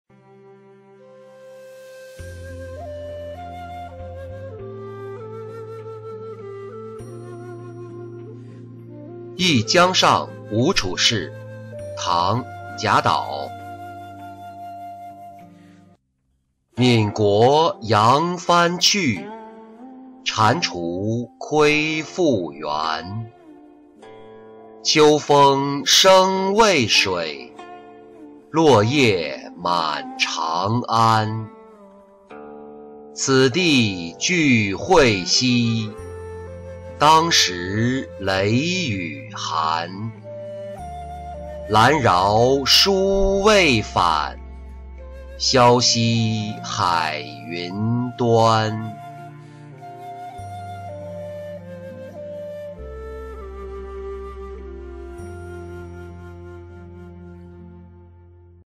忆江上吴处士-音频朗读